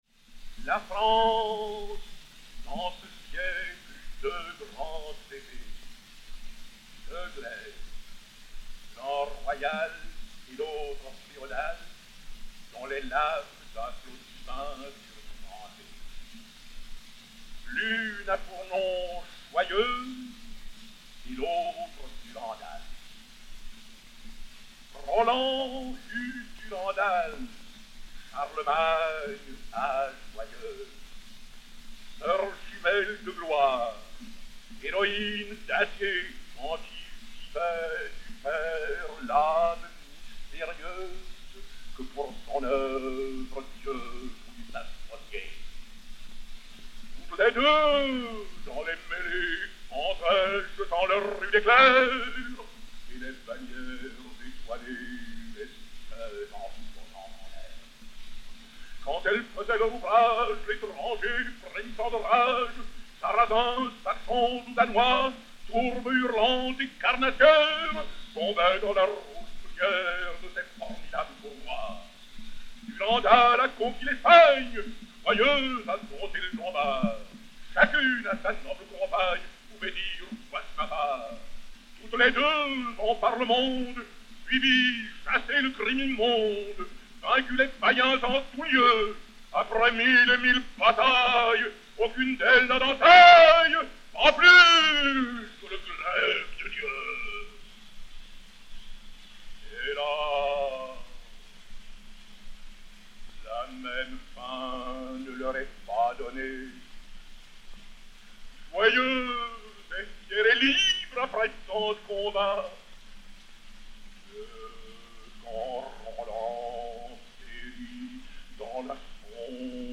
"la France dans ce siècle..." extrait de la Fille de Roland (Henri de Bornier) par Mounet-Sully, disque Pathé saphir 90 tours n° 1132, réédité sur 80 tours n° 4306, enr. en 1912